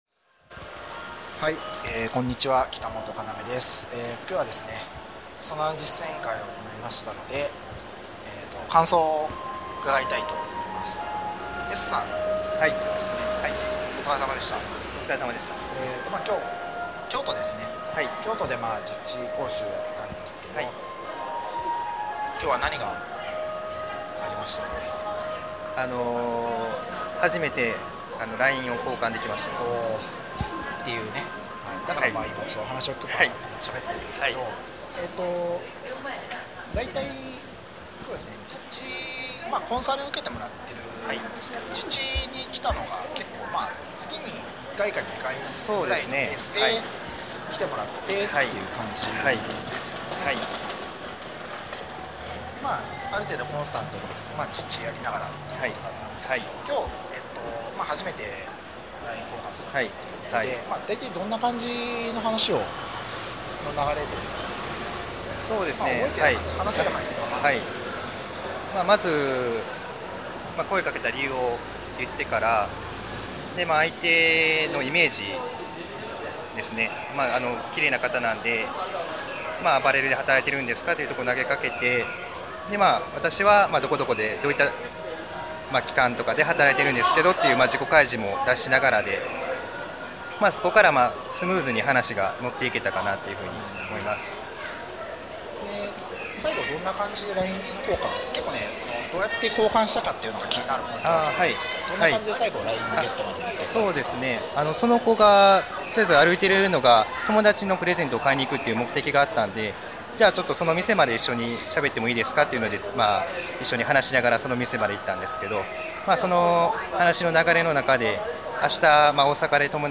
インタビューの中で、この方は